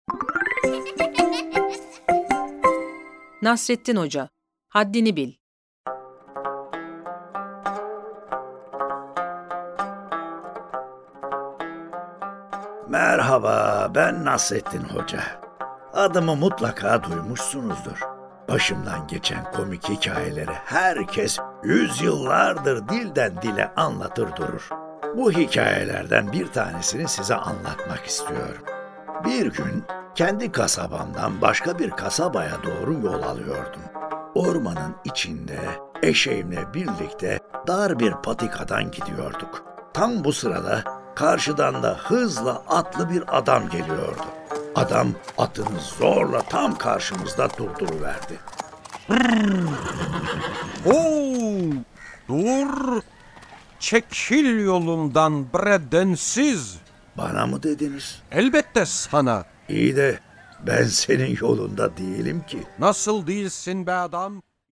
Nasreddin Hoca:Haddini Bil Tiyatrosu